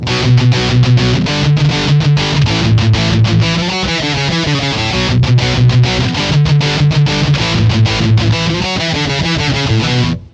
Дисторшн
smash1.mp3 В линию
Через спикерсимулятор
Переключаель edge подрезает самый верх, чуть убирая песок.
В общем хорошая транзисторная педаль с присущими данному классу недостатками - слабая динамика, песок (причем можно нарулить такой, что аж зубы сводит).